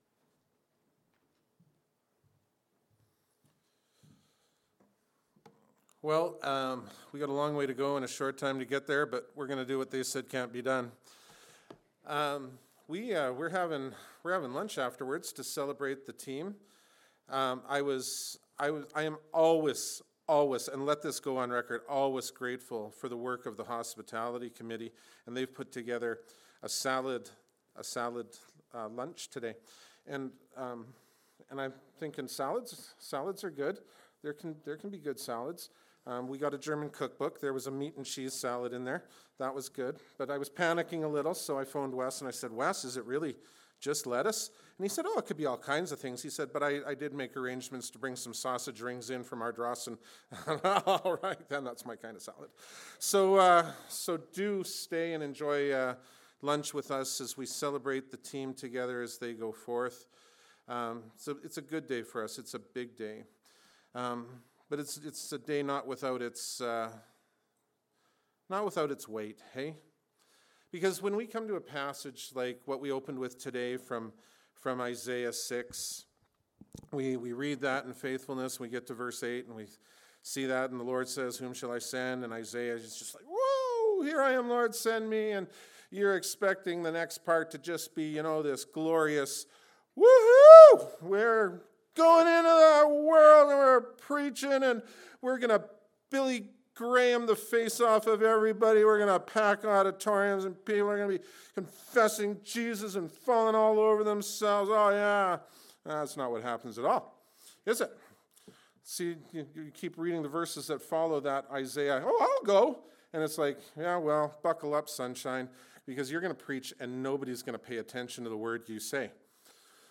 What We All Have in Common – Hillview Baptist Church